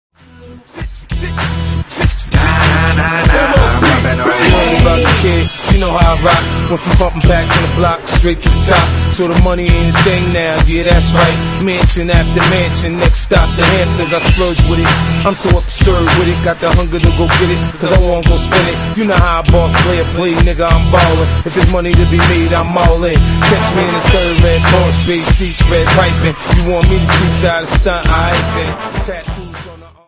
ずばりマッドパーティーチューンです!!
CLEAN